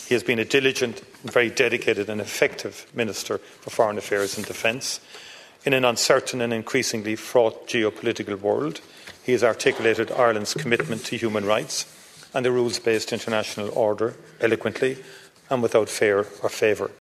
Before the vote took place, Taoiseach Micheál Martin made his confidence in the Tánaiste clear: